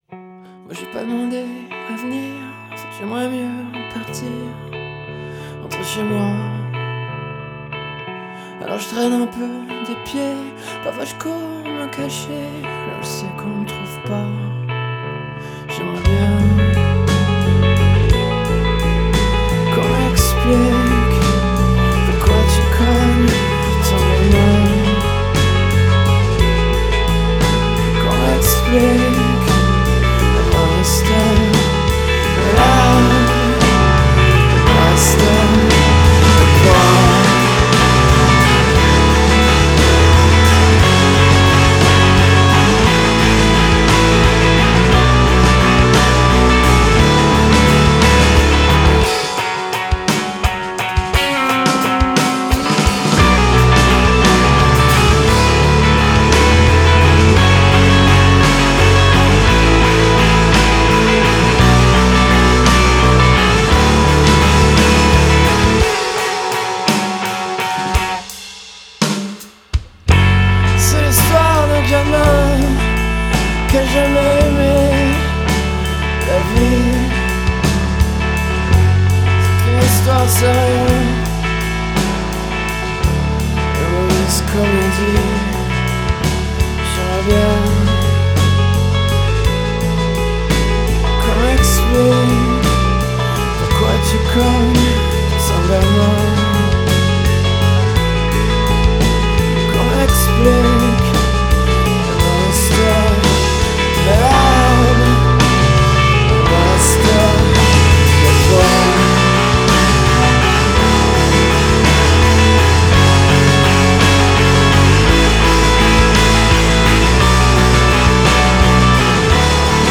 • Genre: Alternative Rock